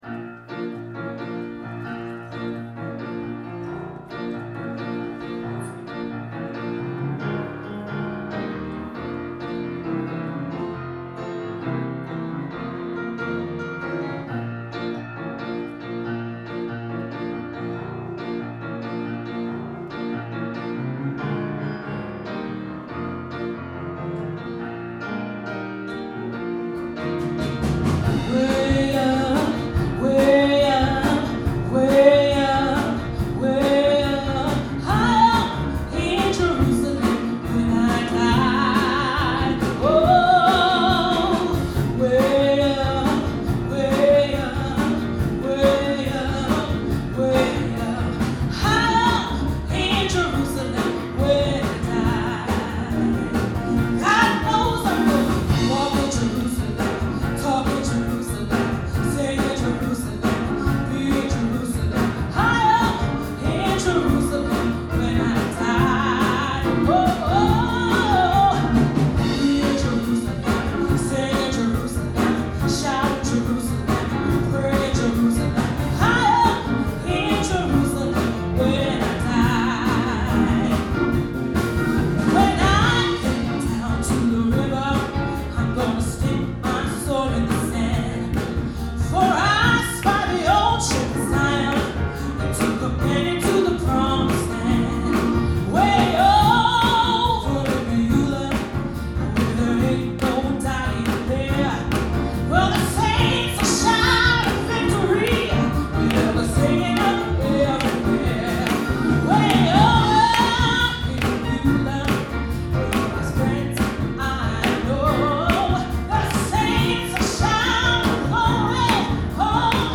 Music from the Christmas Gospel Celebration